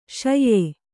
♪ śayye